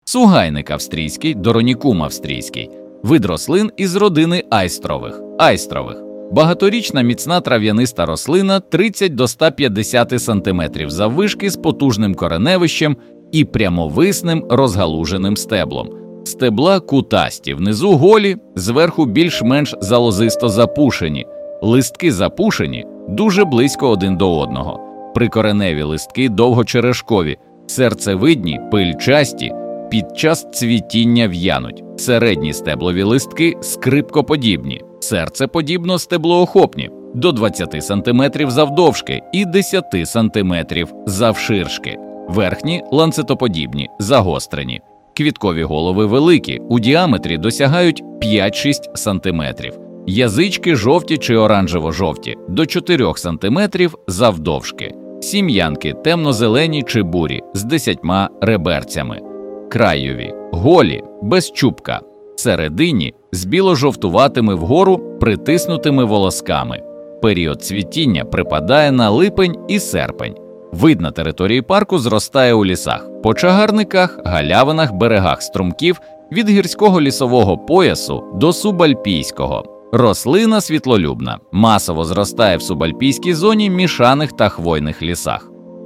Аудіогід